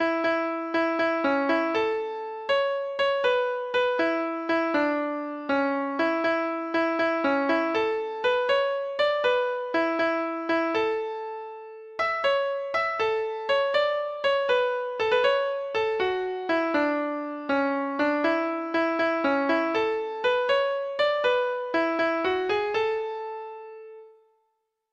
Traditional Trad. The Sentry Box Treble Clef Instrument version
Folk Songs from 'Digital Tradition' Letter T The Sentry Box
Traditional Music of unknown author.